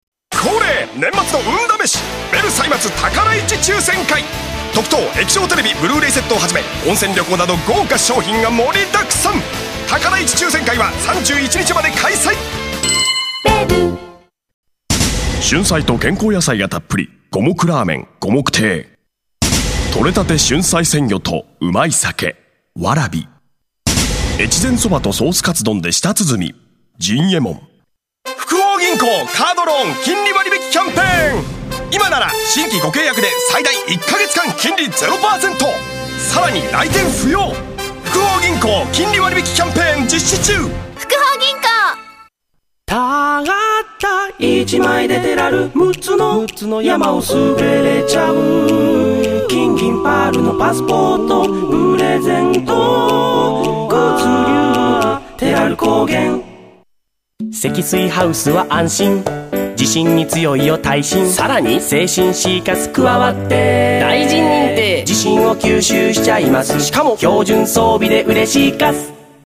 デビューしたレッスン生の、簡単な芸歴とボイスサンプルです。
ボイスサンプル